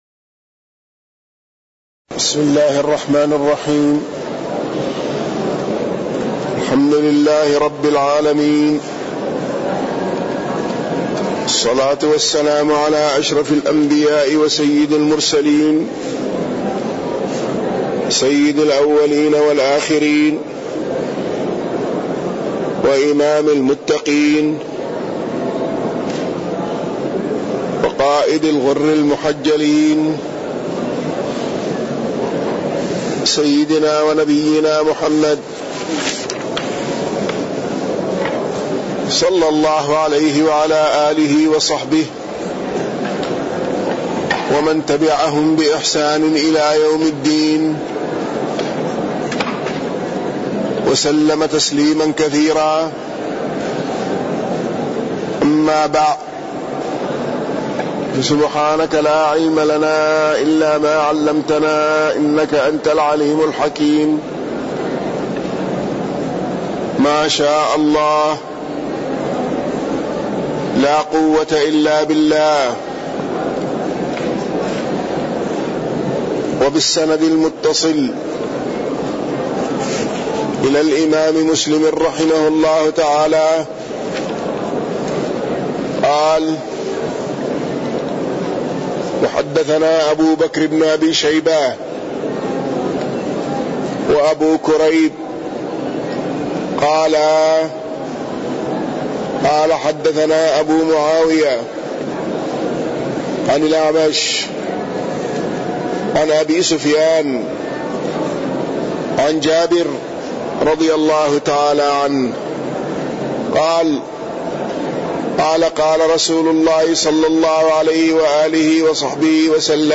تاريخ النشر ٩ ربيع الأول ١٤٣١ هـ المكان: المسجد النبوي الشيخ